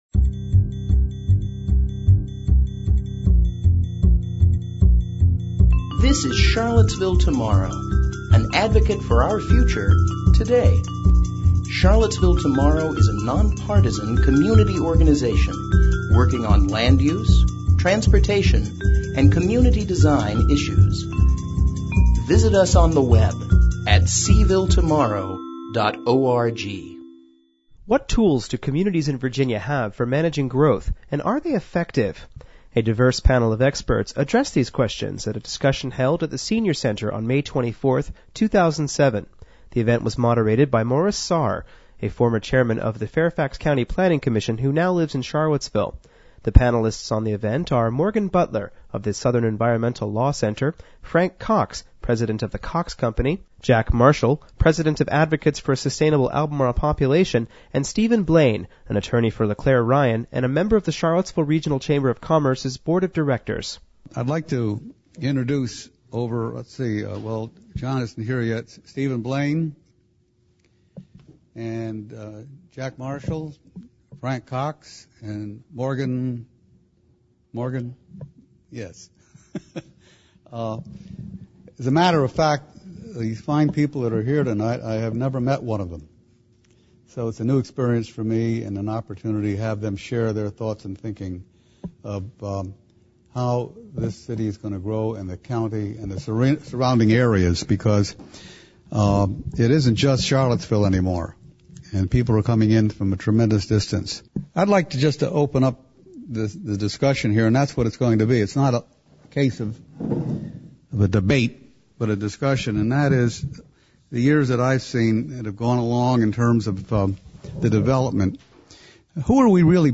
What tools do communities in Virginia have for managing growth, and are they effective? A diverse panel of experts addressed these questions at a discussion held at the Senior Center on May 24, 2007.
The event was moderated by Morris Sahr, a former chairman of the Fairfax County Planning Commission, who now lives in Charlottesville.